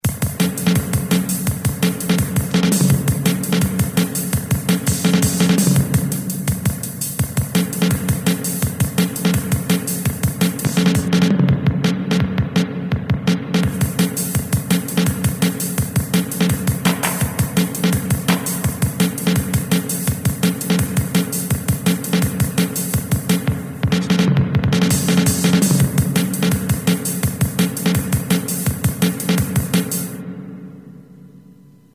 Spring reverb effect unit with limiter system and a special stereo mode.
8 bits drum with Viscount R64 PCM